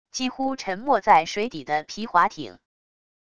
几乎沉没在水底的皮划艇wav音频